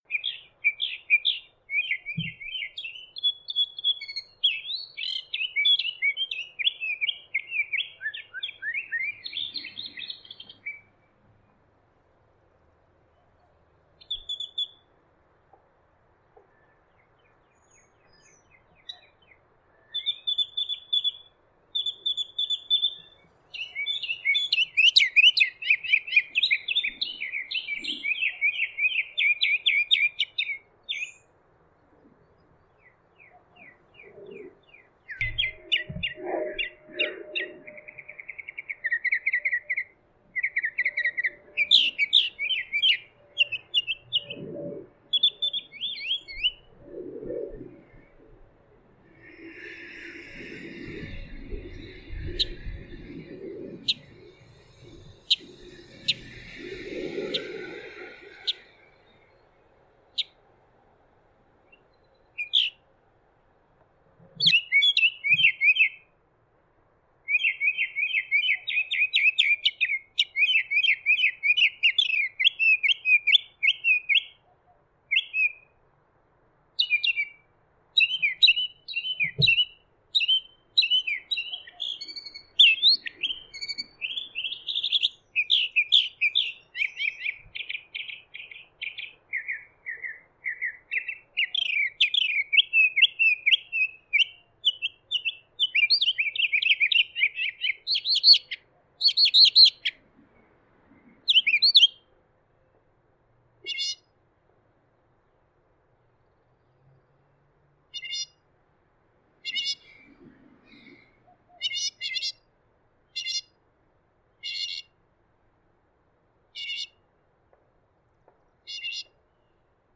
The Sound of the Mockingbird
I captured his song on 5-1-19, in East Dallas Junius Heights District, at the corner of Beacon and Victor. I used a parabolic microphone of my own design (see picture.) It's 14 minutes of clear and continuous output, from the top of the mockingbirds's favorite telephone pole. I used the audio app Audacity to clean it up. It contains at least 30 different bird calls, songs, and general squawks.
MockingBird_E-Dallas_5-1-19.mp3